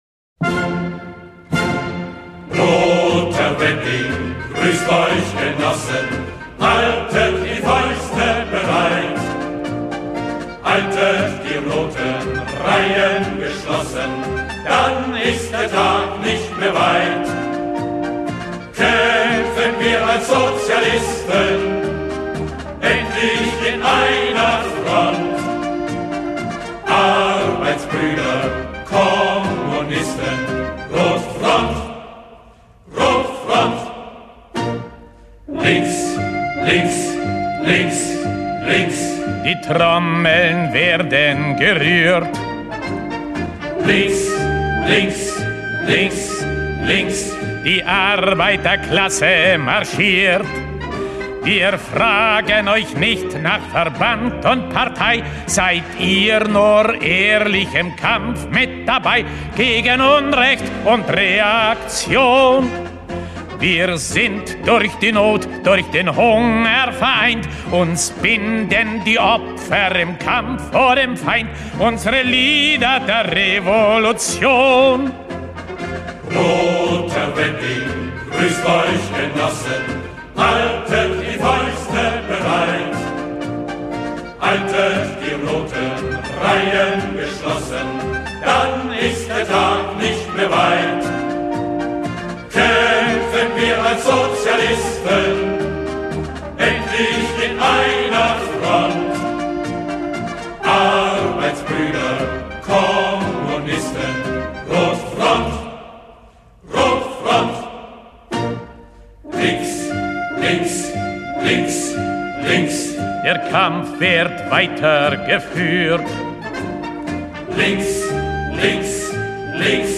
Запись 1960-х гг.